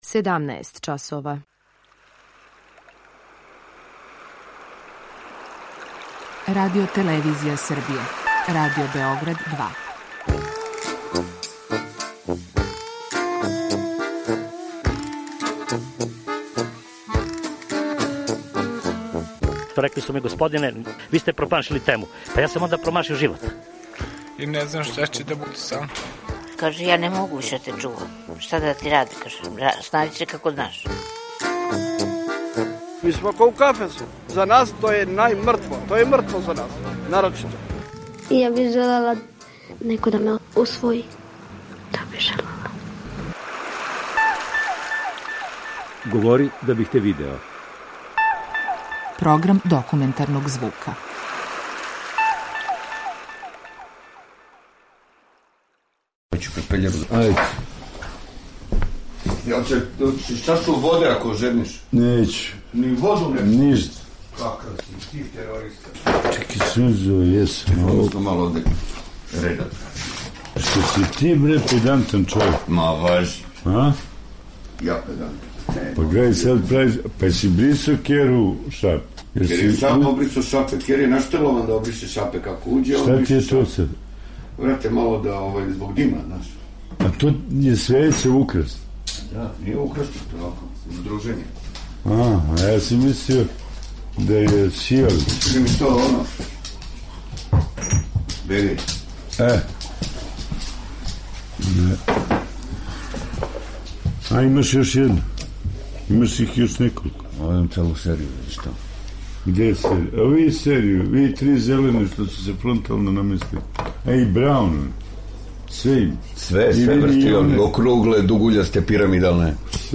Документарни програм
О стрпљењу, лепоти живота, доношењу одлика говори човек из околине.